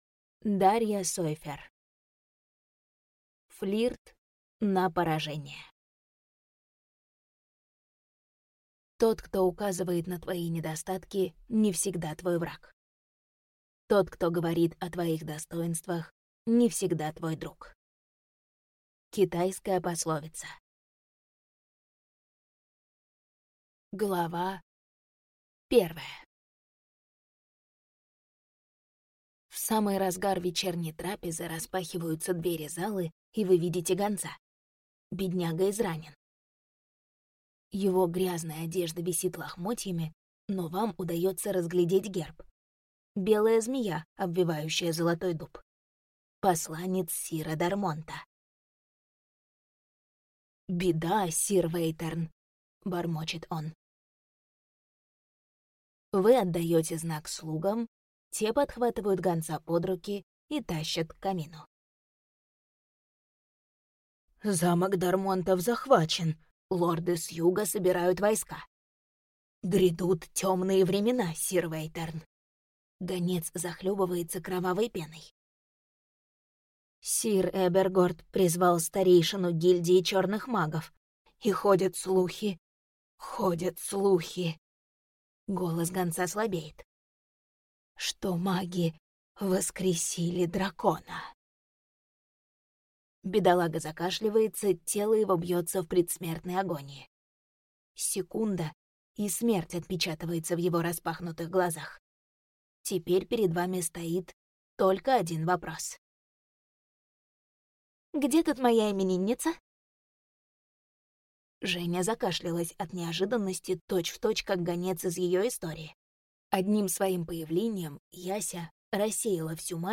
Аудиокнига Флирт на поражение | Библиотека аудиокниг